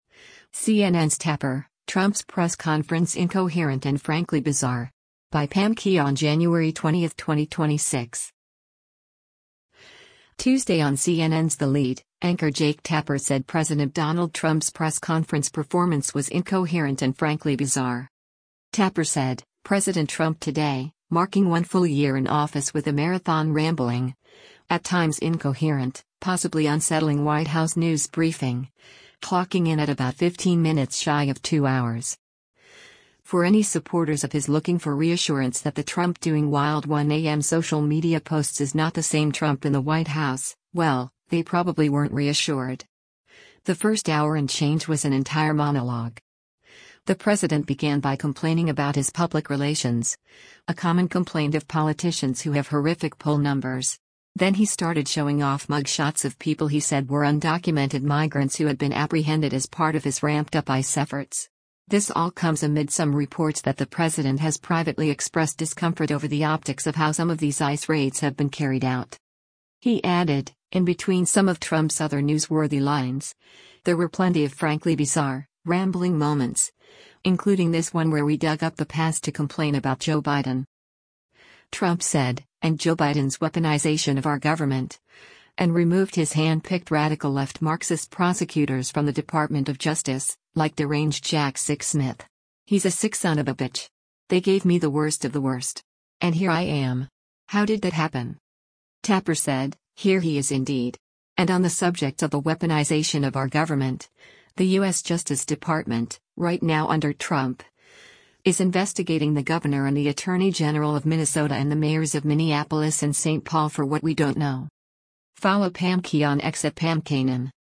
Tuesday on CNN’s “The Lead,” anchor Jake Tapper said President Donald Trump’s press conference performance was “incoherent” and “frankly bizarre.”